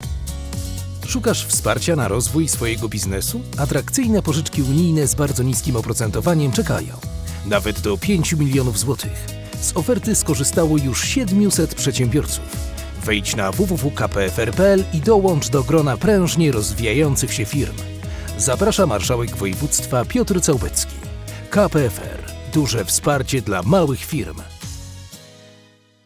2019-spot-radiowy-2.wav